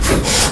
hydraulic_stop01.wav